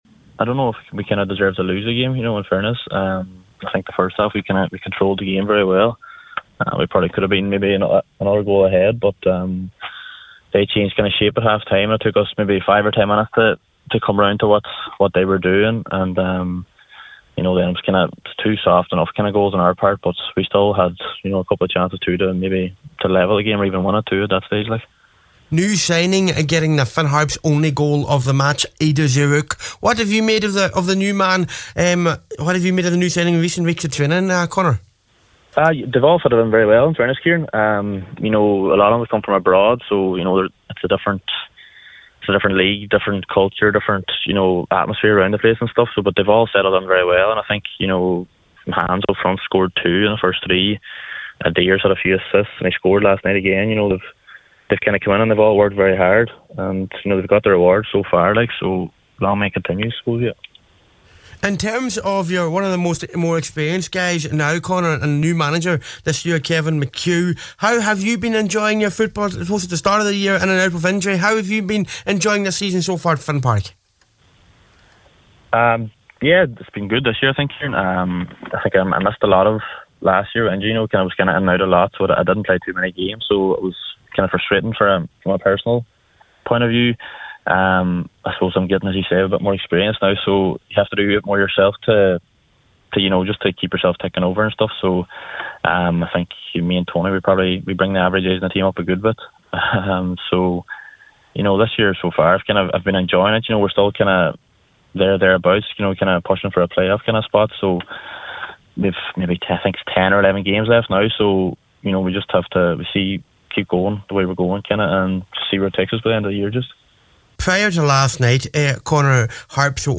on Highland Radio Saturday Sport this afternoon.